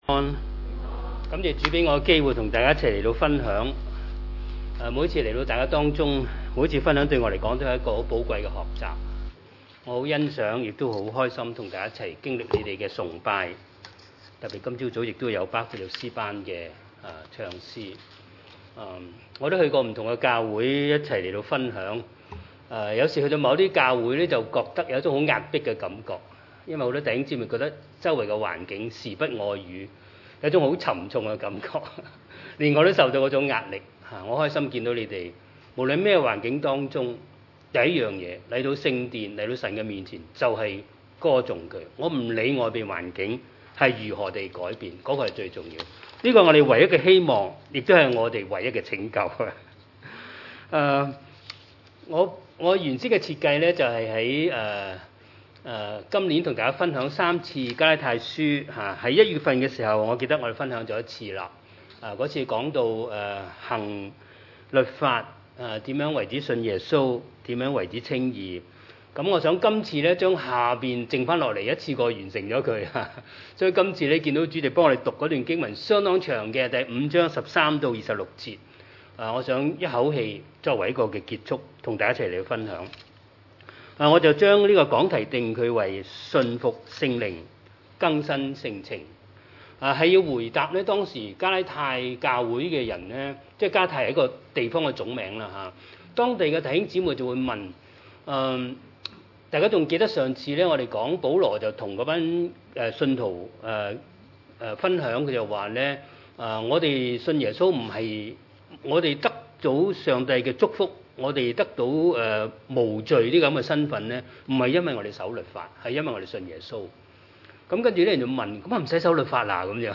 加拉太書 五：13-26 崇拜類別: 主日午堂崇拜 弟兄們，你們蒙召是要得自由，只是不可將你們的自由當作放縱情慾的機會，總要用愛心互相服事。